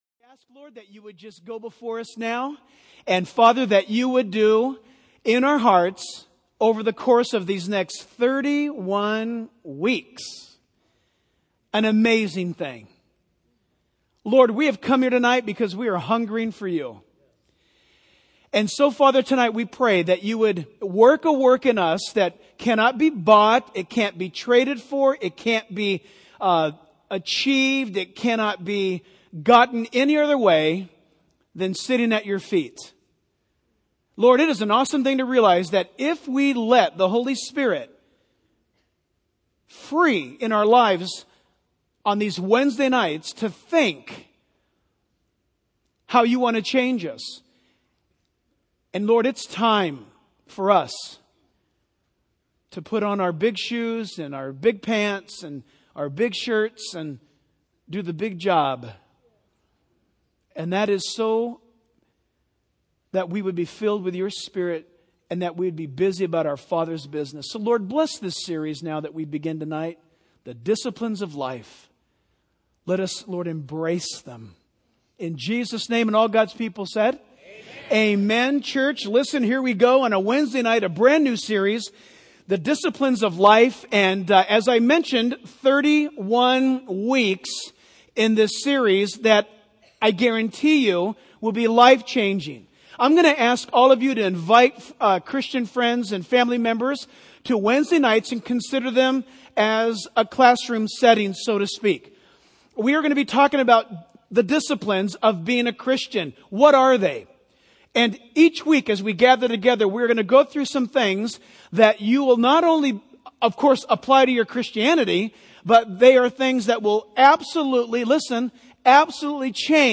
In this sermon, the preacher introduces a new series called 'The Disciplines of Life' which will span over 31 weeks. The purpose of this series is to explore the disciplines of being a Christian and how they can transform every aspect of our lives.